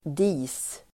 Uttal: [di:s]